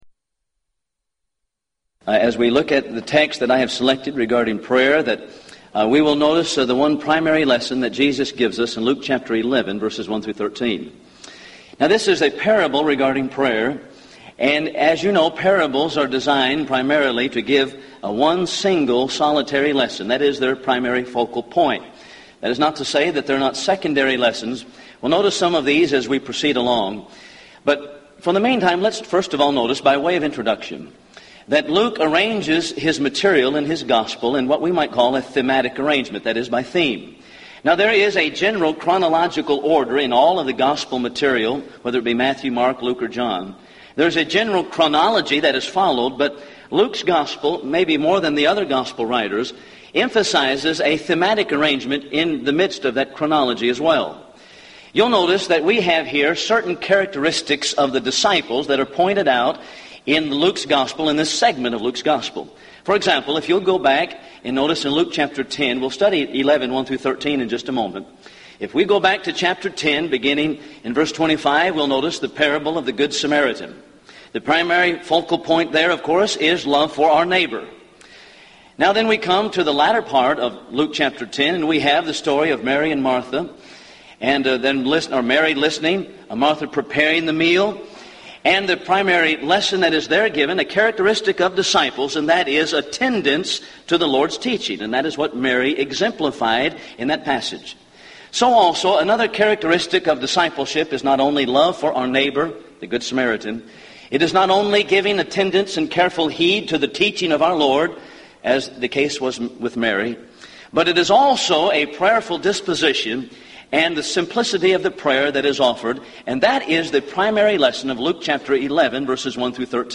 Event: 1998 Gulf Coast Lectures Theme/Title: Prayer and Providence
lecture